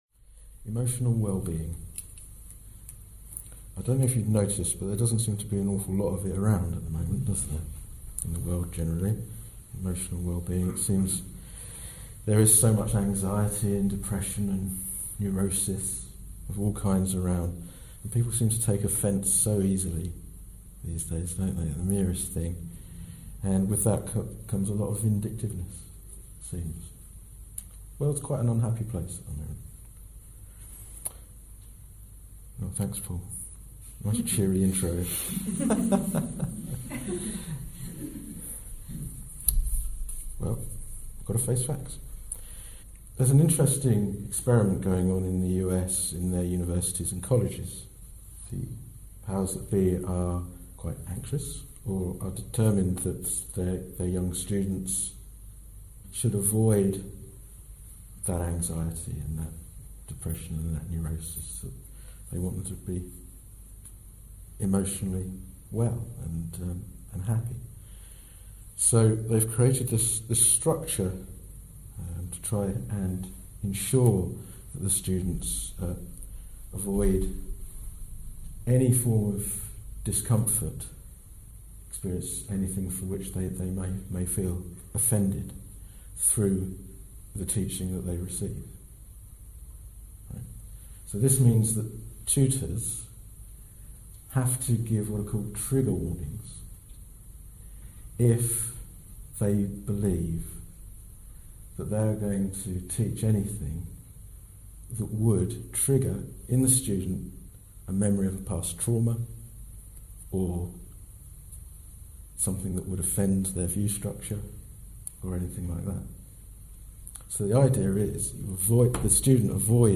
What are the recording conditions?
This talk was given in August 2015